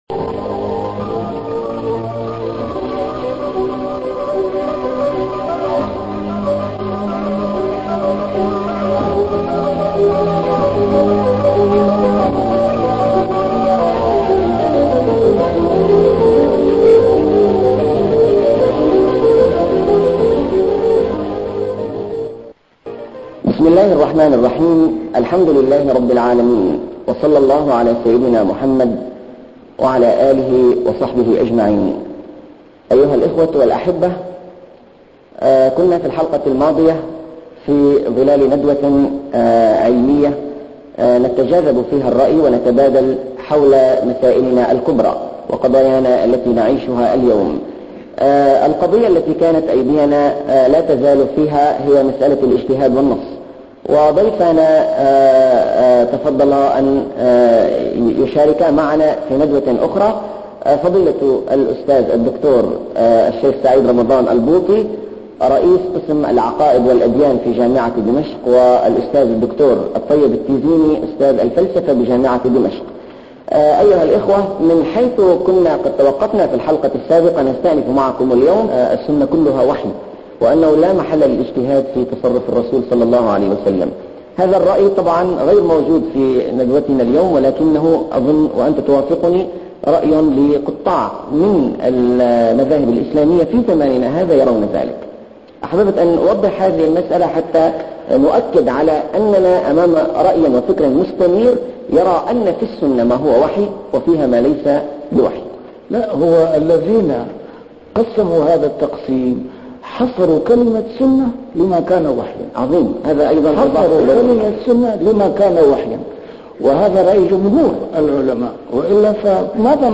A MARTYR SCHOLAR: IMAM MUHAMMAD SAEED RAMADAN AL-BOUTI - الدروس العلمية - محاضرات متفرقة في مناسبات مختلفة - مناظرة مع الدكتور الطيب التيزيني حول الاجتهاد والنص - الحلقة الثانية
محاضرات متفرقة في مناسبات مختلفة - A MARTYR SCHOLAR: IMAM MUHAMMAD SAEED RAMADAN AL-BOUTI - الدروس العلمية - مناظرة مع الدكتور الطيب التيزيني حول الاجتهاد والنص - الحلقة الثانية